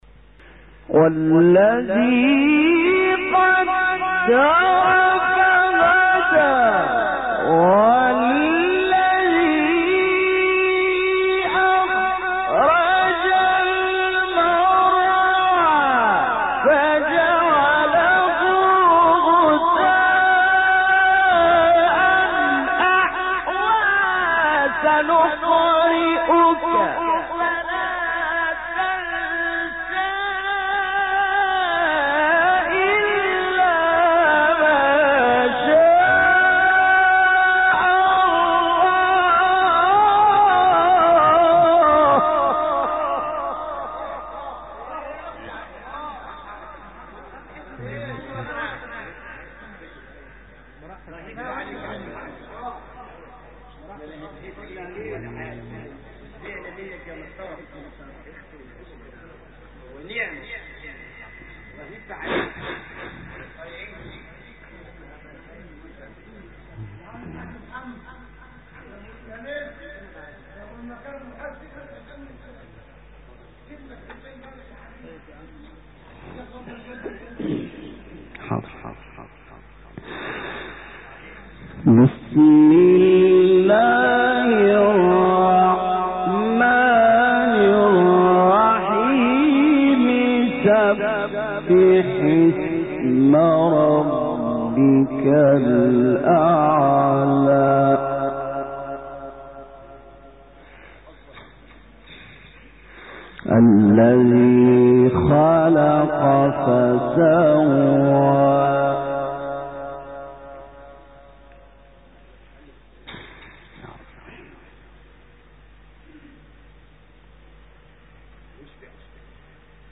سوره : اعلی آیه : 1-7 استاد : محمد لیثی مقام : رست قبلی بعدی